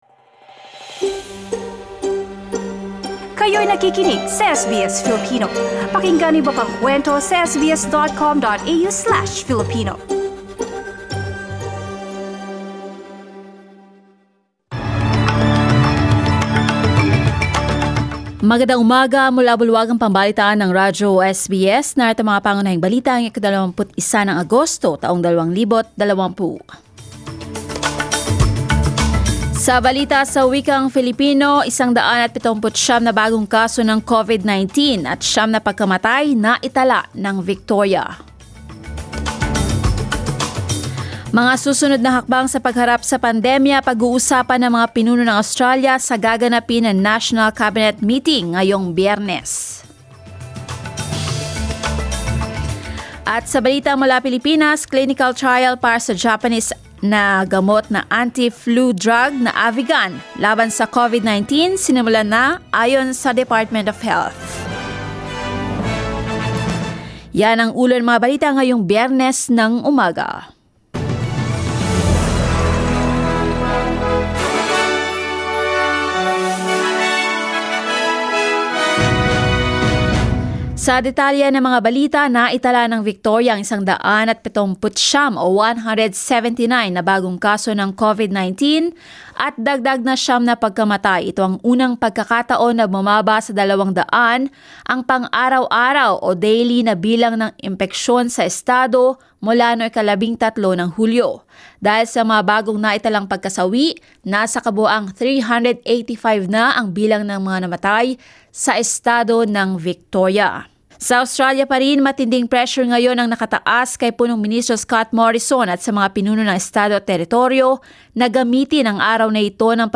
SBS News in Filipino, Friday 21 August